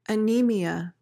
PRONUNCIATION:
(uh-NEE-mee-uh)